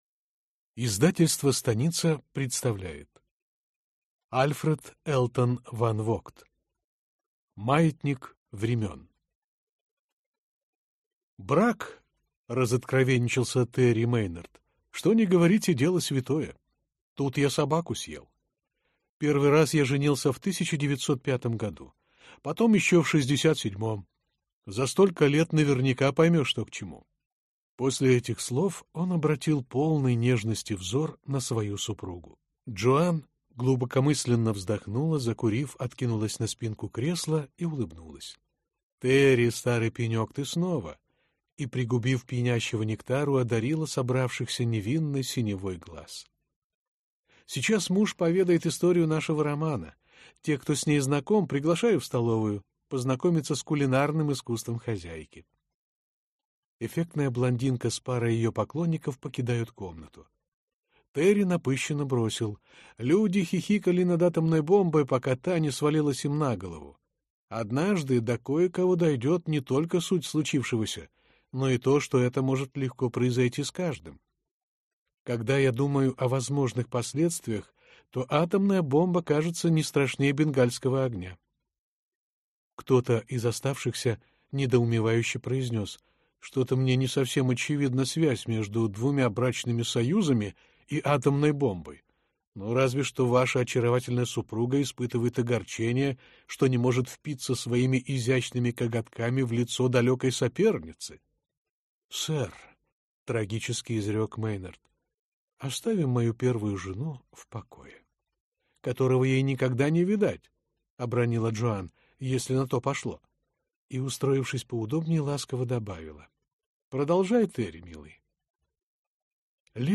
Аудиокнига Маятник времён | Библиотека аудиокниг